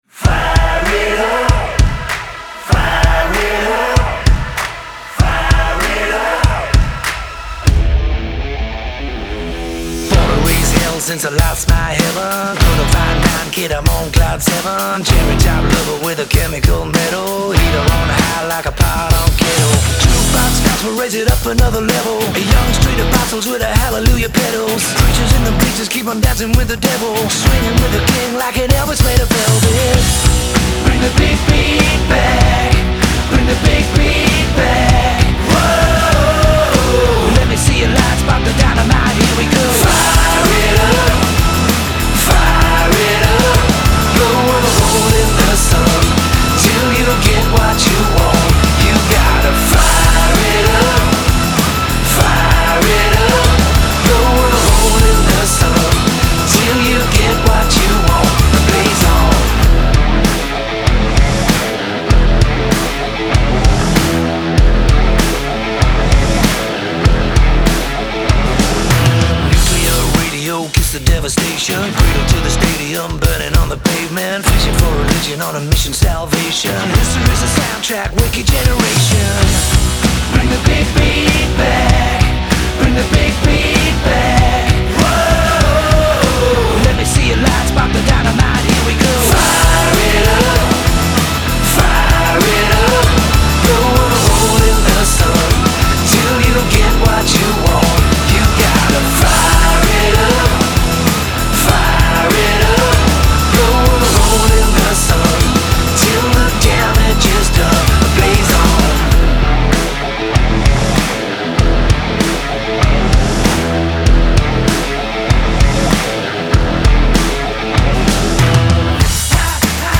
Genre : Rock, Pop